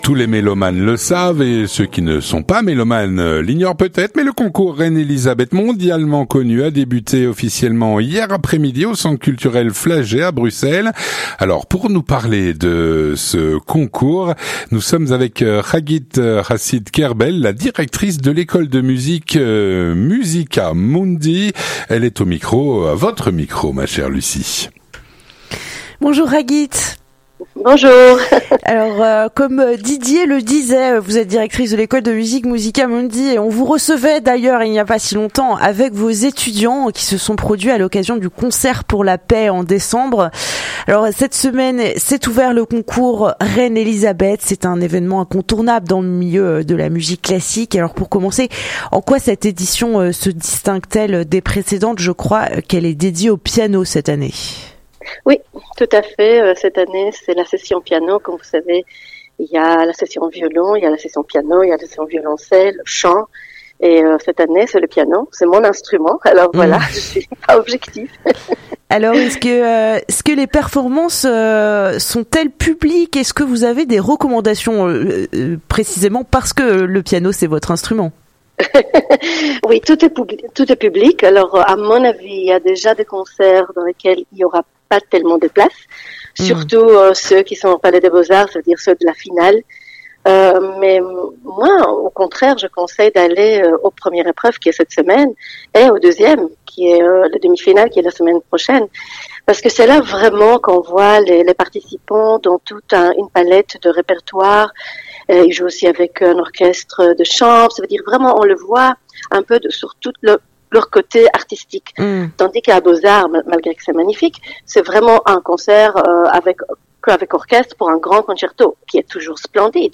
Rencontre - Le Concours Reine Elisabeth a débuté hier après-midi au centre culturel Flagey, à Bruxelles.